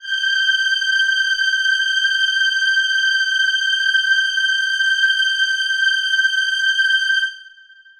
Choir Piano